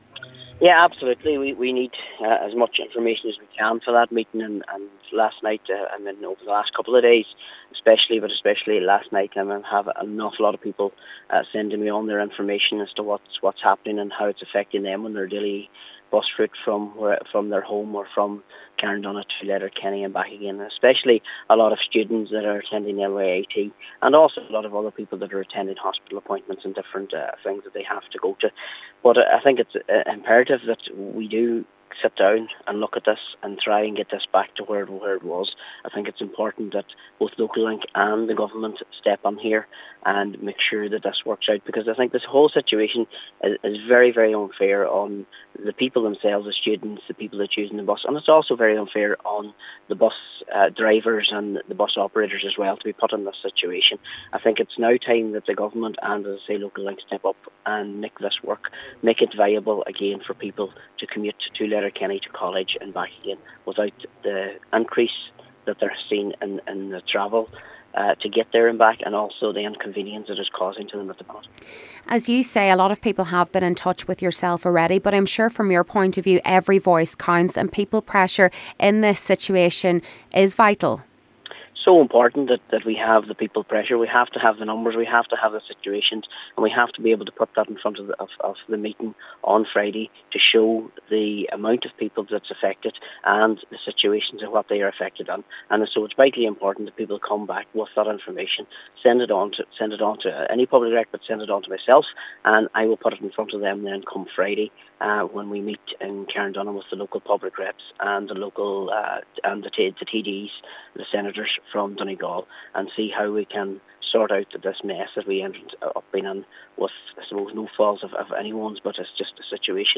He says having as much information as possible will only strengthen the case: